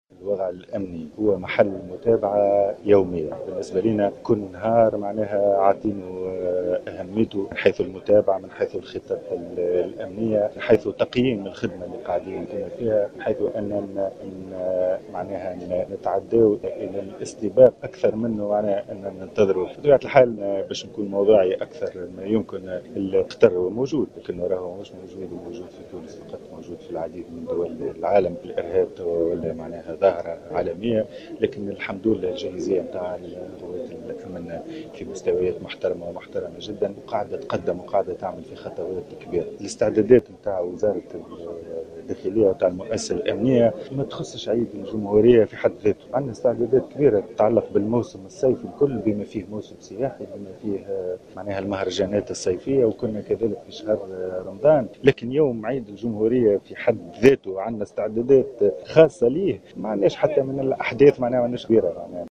قال وزير الداخلية الهادي مجدوب في تصريح